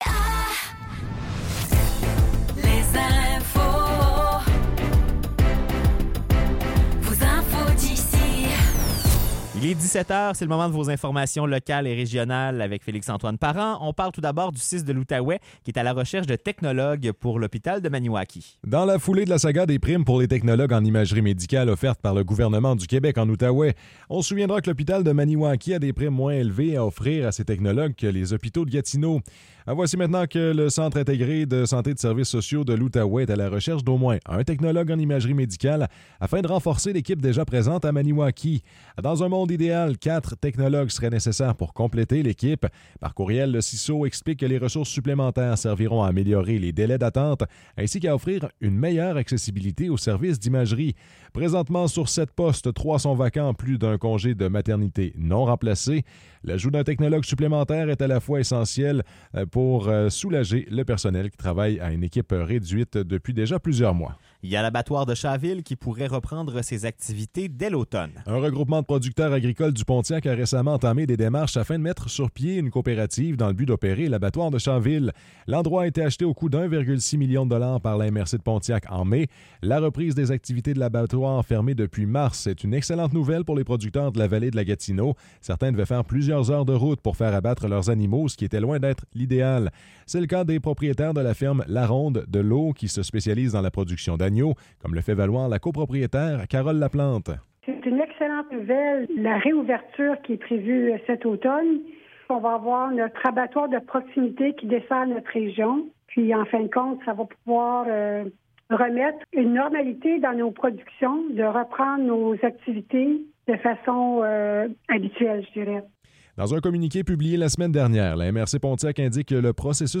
Nouvelles locales - 1 août 2024 - 17 h